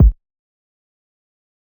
rack kick.wav